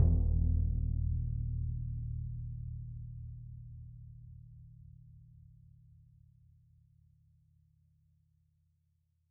bassdrum_roll_mf_rel.wav